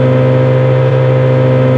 rr3-assets/files/.depot/audio/sfx/electric/mp4x_off_mid_7740rpm.wav
mp4x_off_mid_7740rpm.wav